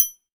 PMTRIANGLE.wav